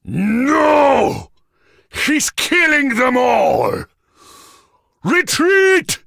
Sounds / Enemys / Giant